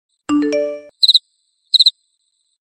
Nada Dering WA ‘Jangkrik’
Genre: Nada dering binatang
Suaranya tuh alami banget, kayak denger jangkrik beneran di malam hari – adem tapi juga bikin suasana chatting jadi lebih santai dan nggak monoton.
nada-dering-wa-jangkrik.mp3